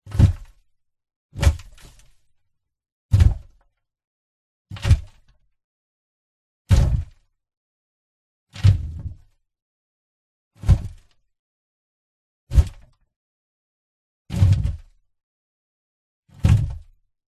Звук погребения в гробу: земля и грязь, записанные изнутри, POV, последовательность